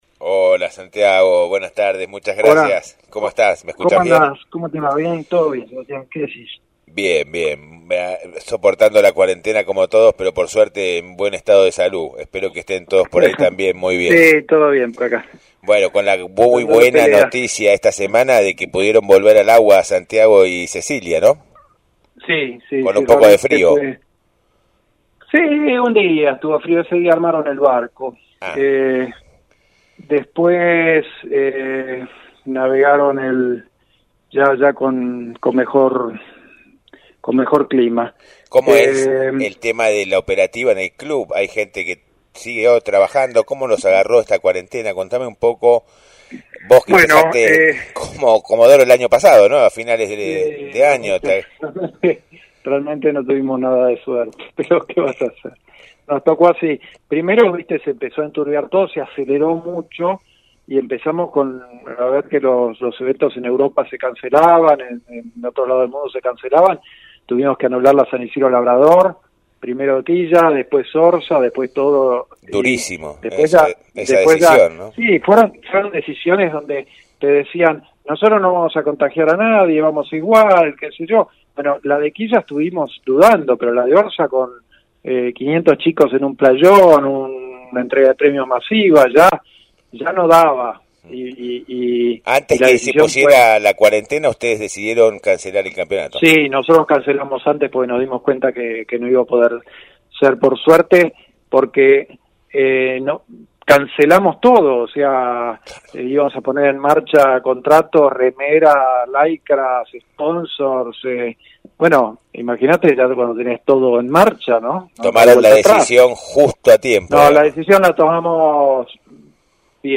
Noticias N�uticas